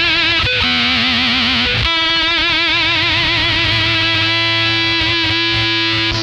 Index of /90_sSampleCDs/Zero G - Funk Guitar/Partition I/VOLUME 001